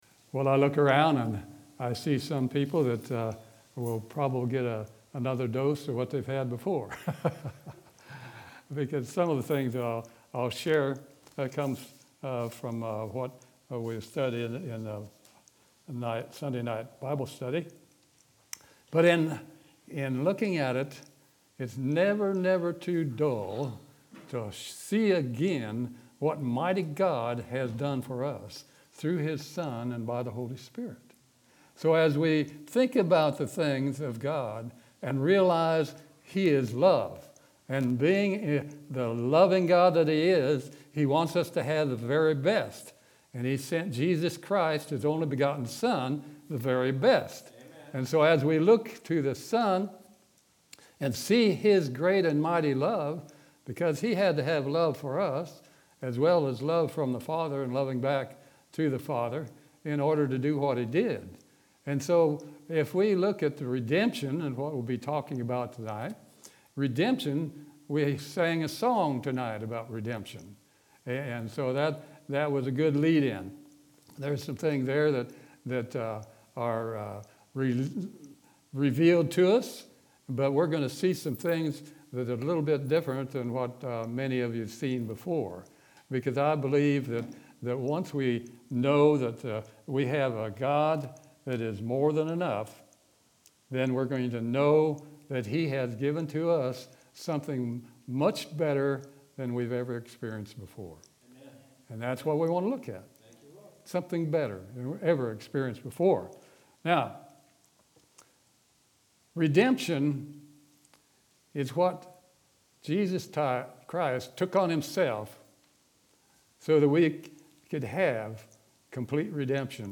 Sermon from Wednesday, November 11th, 2020.